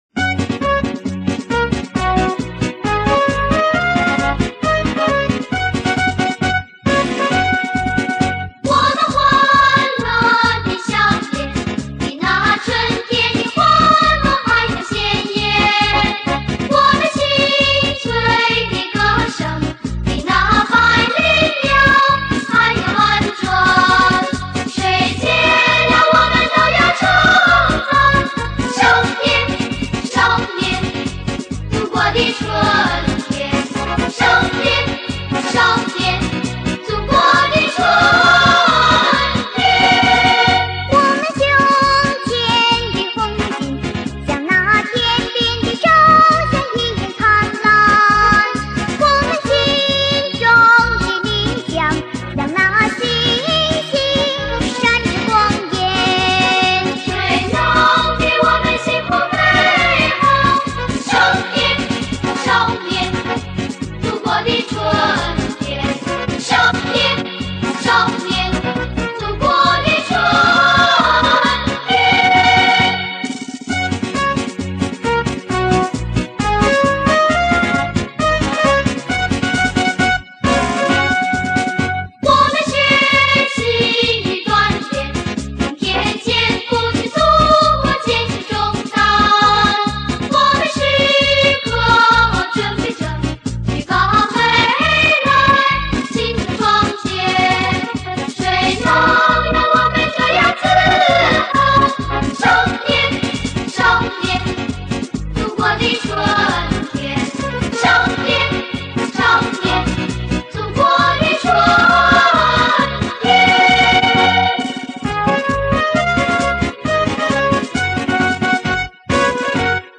经典儿歌